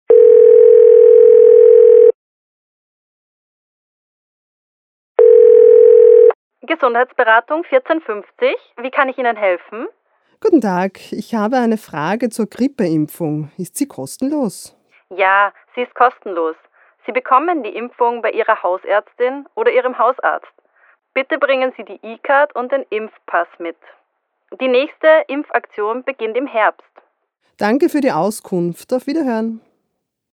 „Gespräch c"
DLM_Dialog_3.mp3